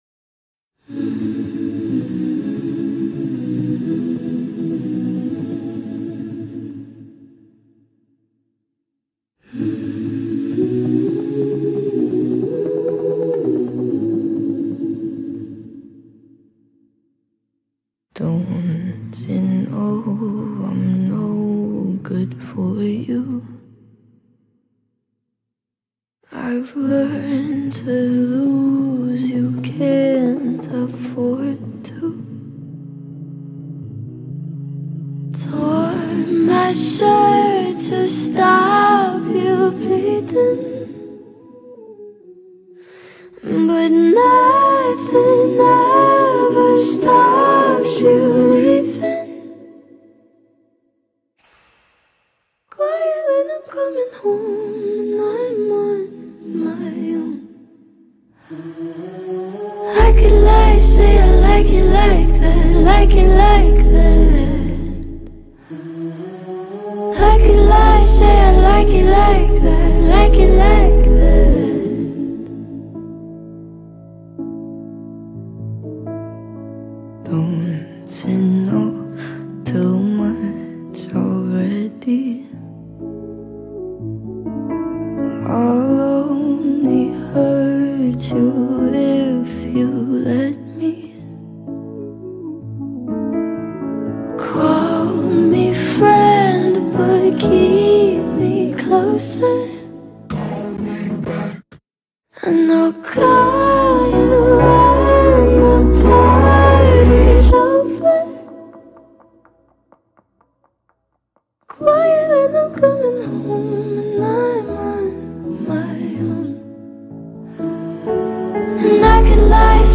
female English music artist